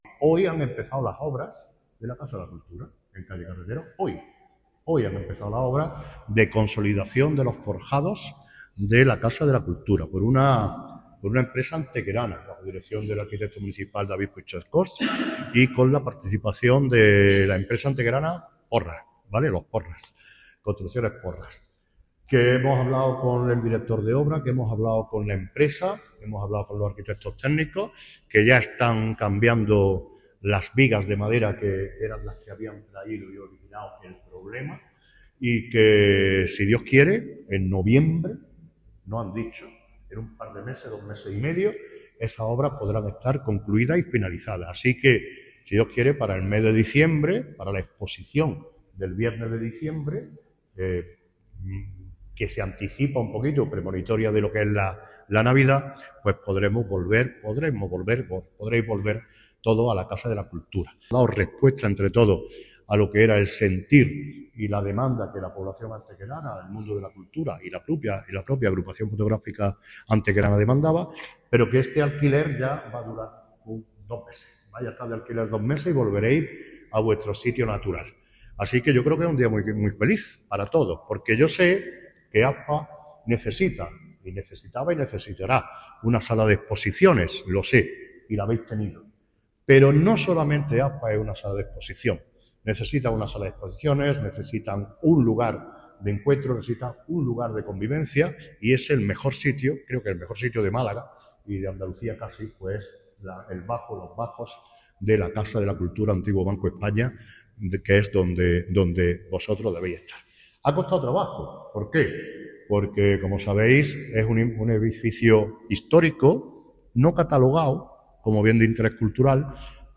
Durante la visita de inicio de las obras, el alcalde Manolo Barón ha subrayado la importancia de la actuación: “Hoy han empezado las obras de consolidación de la Casa de la Cultura, que permitirán resolver un problema estructural del edificio y devolverle su plena funcionalidad como espacio cultural y de convivencia. Si todo marcha según lo previsto, a finales de noviembre o principios de diciembre estará concluida la reforma y podremos volver a acoger actividades y exposiciones en un lugar único, de los mejores de Málaga y Andalucía”.
Cortes de voz